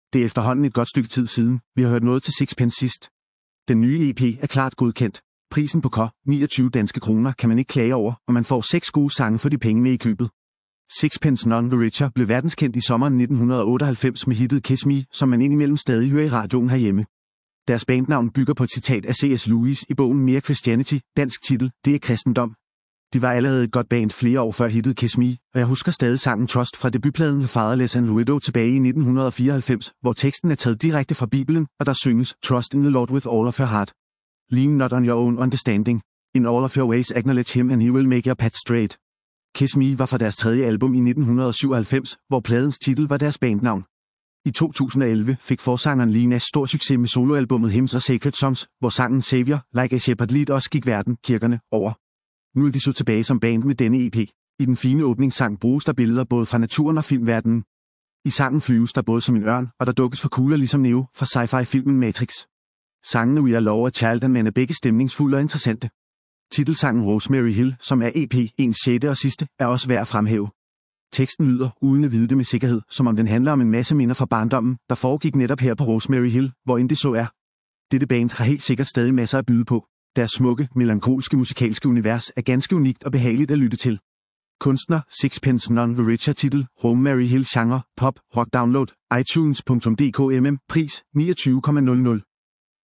Genre: Pop/Rock